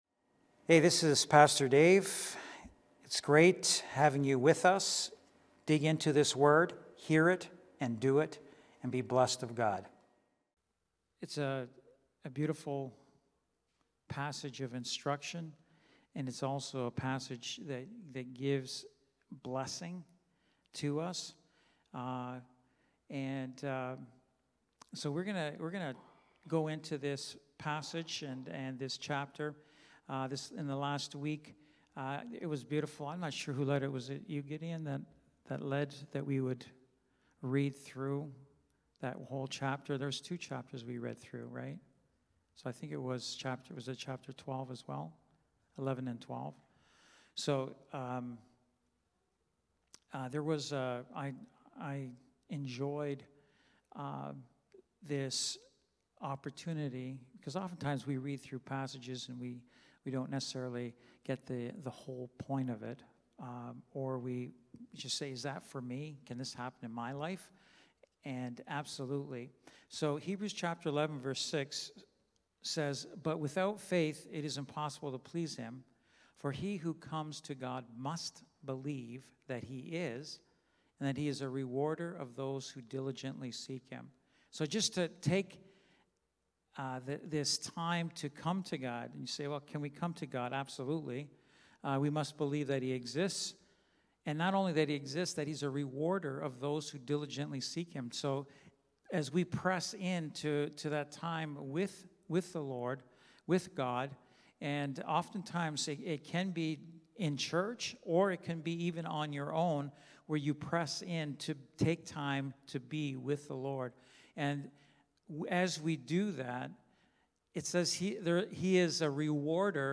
Wednesday Night Bible Study